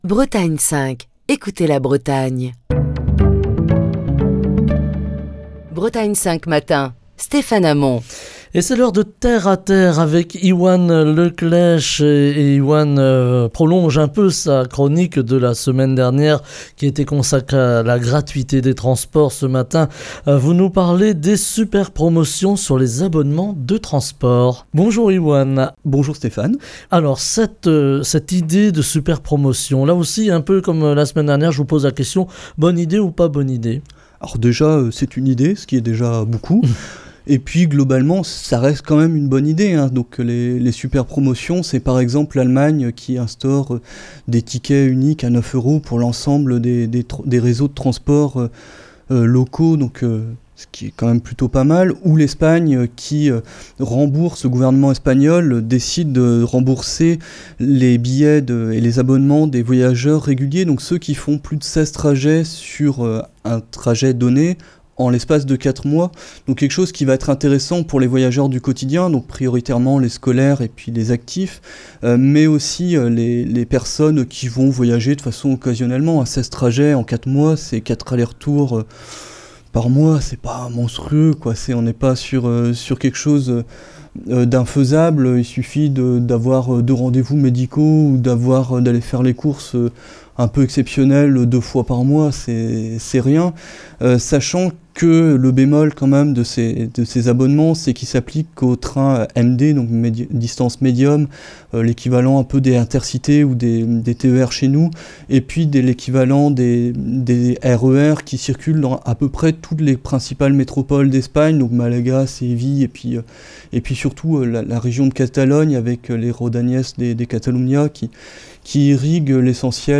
Chronique du 3 novembre 2022.